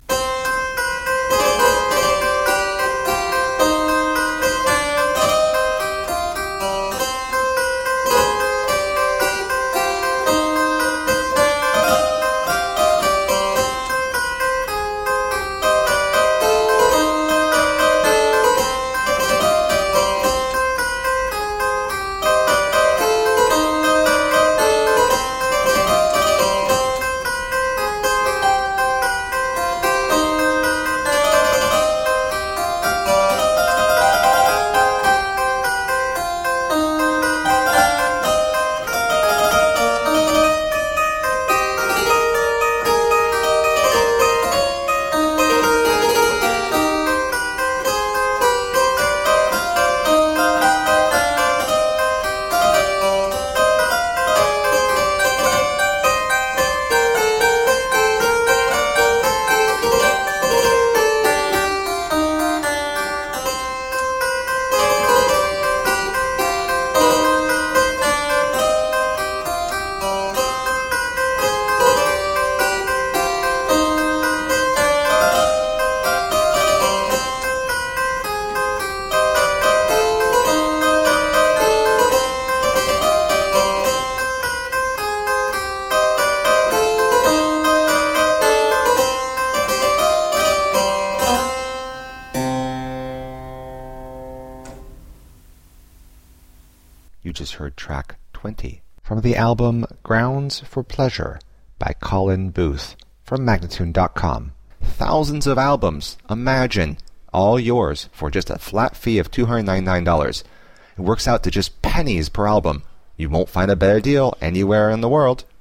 Solo harpsichord music.
Classical, Baroque, Renaissance, Instrumental
Harpsichord